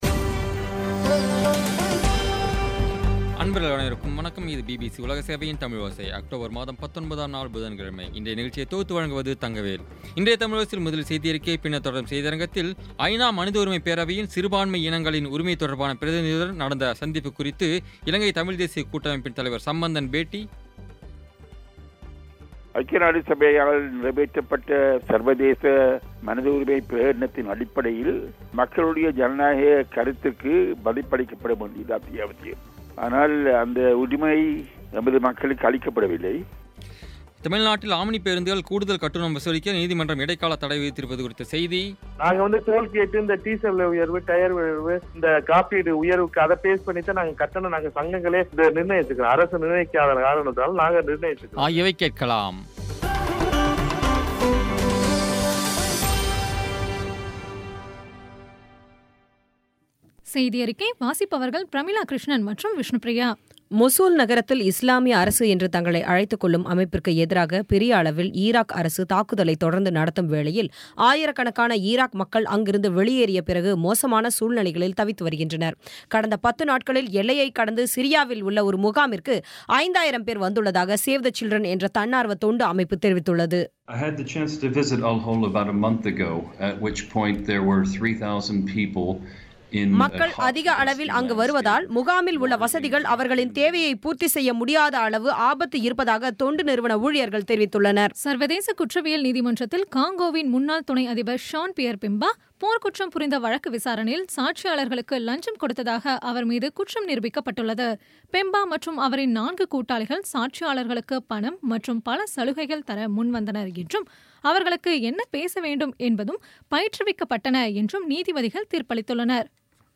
இன்றைய தமிழோசையில், ஐ .நா. மனித உரிமை பேரவையின், சிறுபான்மை இனங்களின் உரிமை தொடர்பான பிரதிநிதியுடன் நடந்த சந்திப்புக் குறித்து, இலங்கை தமிழ் தேசியக் கூட்டமைப்பின் தலைவர் சம்பந்தன் பேட்டி தமிழ்நாட்டில் ஆம்னி பேருந்துகள் கூடுதல் கட்டணம் வசூலிக்க நீதிமன்றம் இடைக்காலத் தடை விதித்திருப்பது குறித்த செய்தி ஆகியவை கேட்கலாம்.